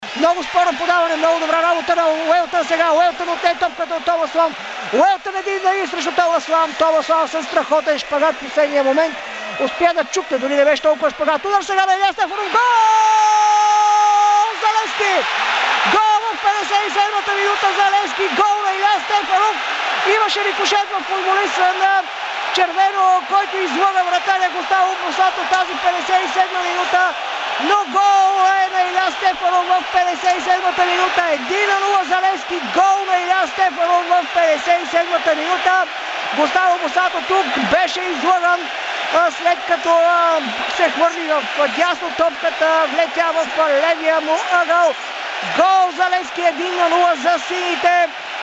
Голът, оцветил Купата в синьо (аудиокоментар)
на живо в ефира на Дарик радио.